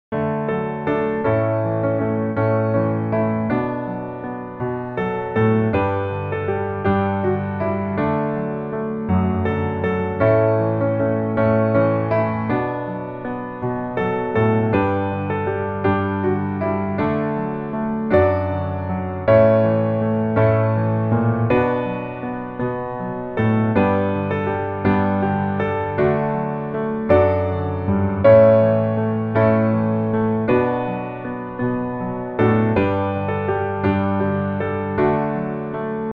D Major
Singing, mp3, MIDI (song), MIDI (tune)